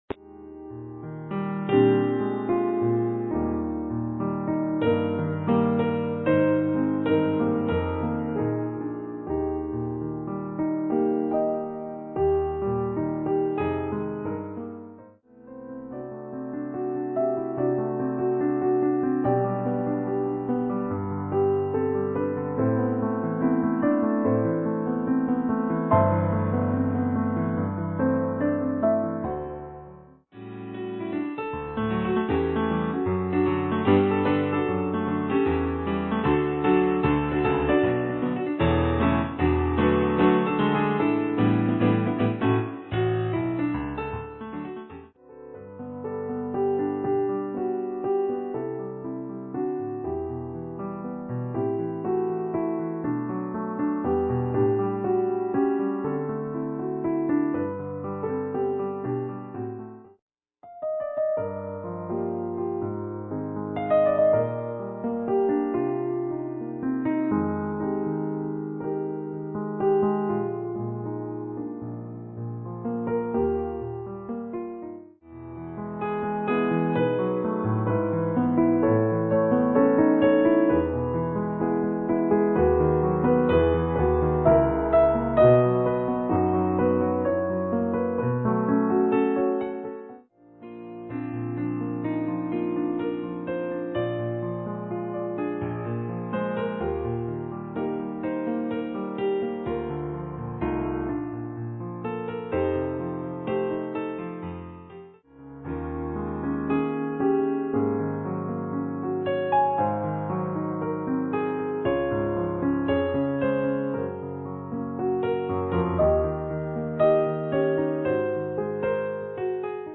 Instrumental/vocal